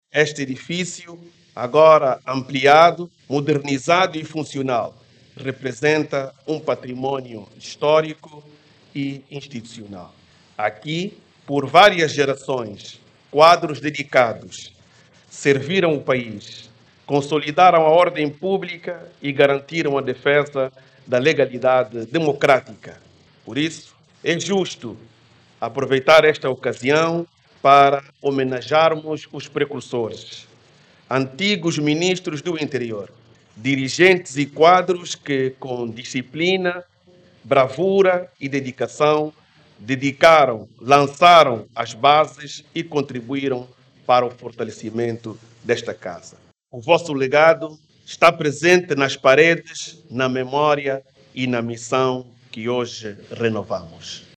As declarações foram feitas durante a cerimónia de reinauguração das actuais infraestruturas do Ministério do Interior, que foram ampliadas com um novo edifício.